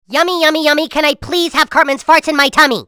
Farts Pt3 Sound Effect Free Download
Farts Pt3